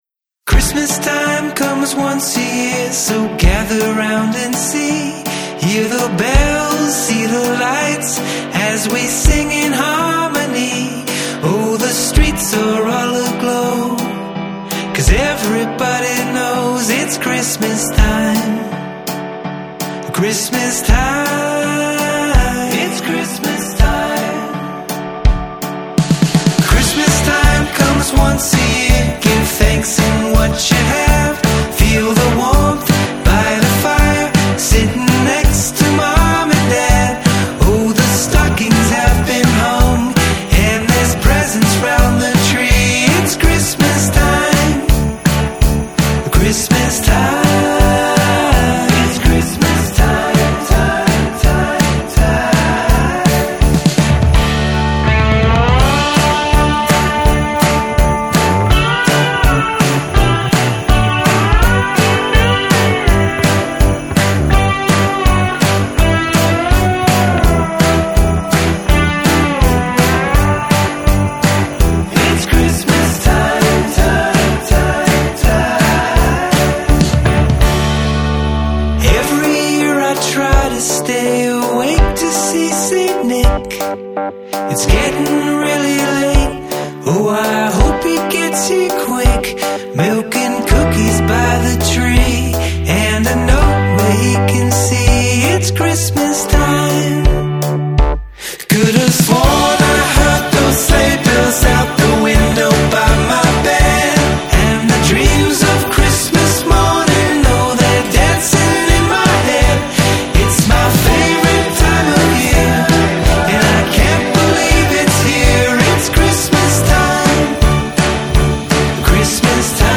a holiday song